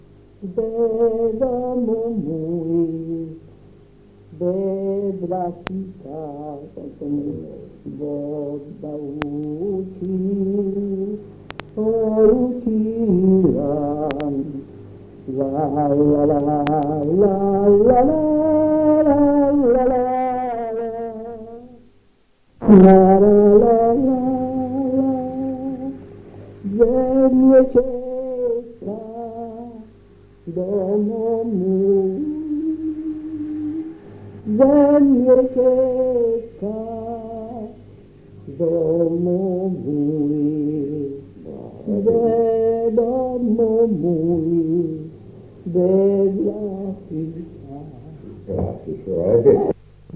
7 giugno 1978». 1975. 1 bobina di nastro magnetico.